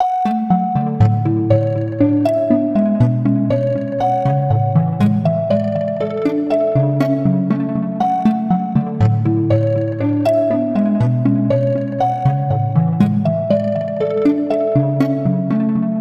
Plucks.wav